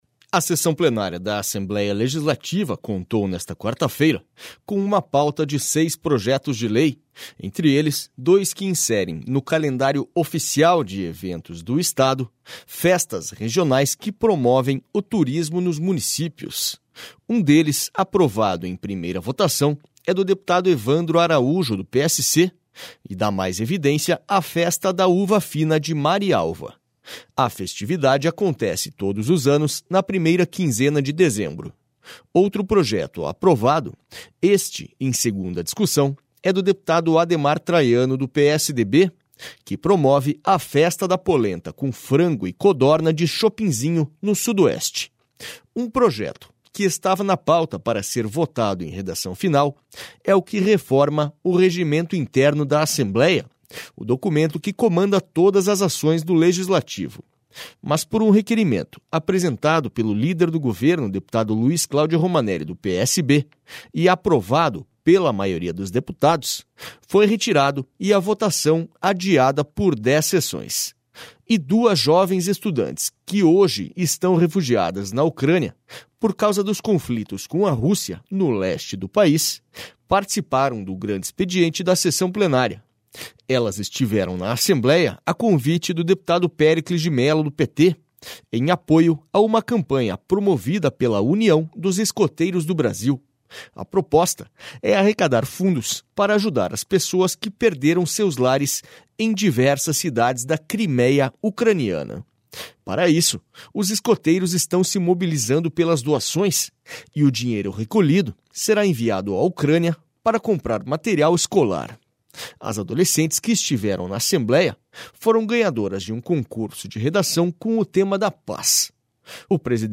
SONORA ADEMAR TRAIANO